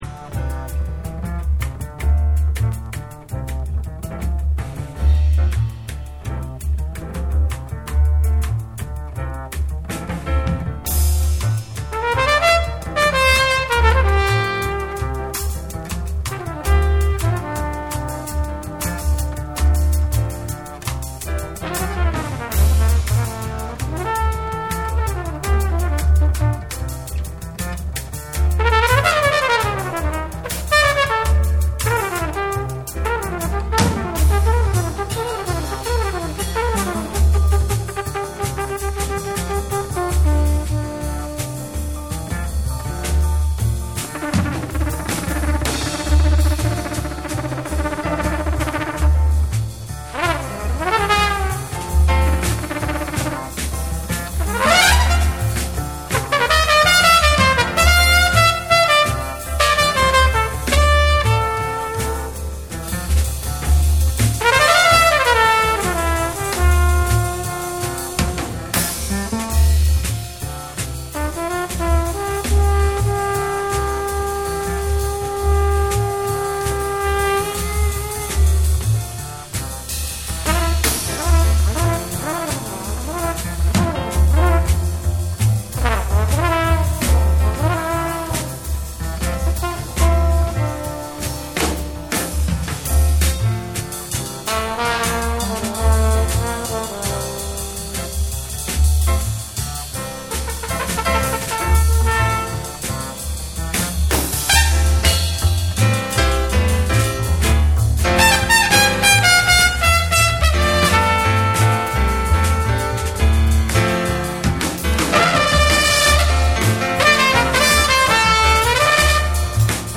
SOUL & FUNK & JAZZ & etc